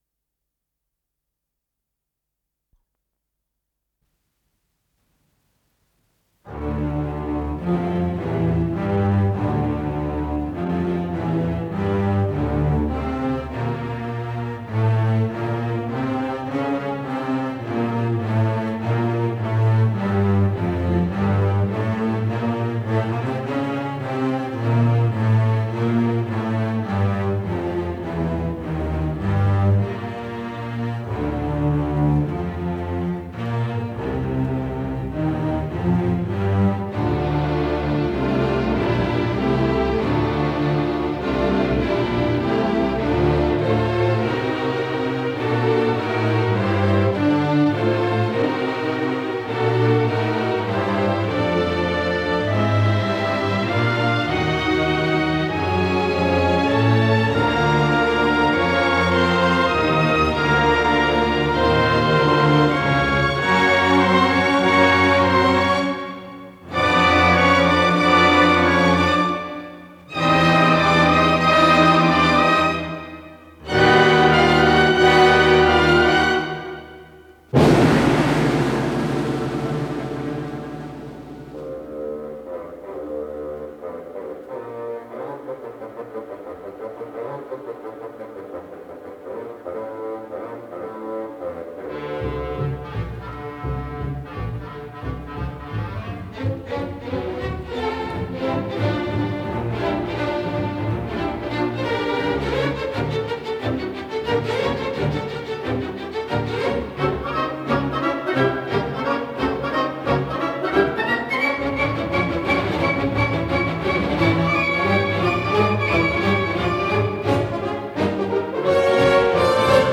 ре минор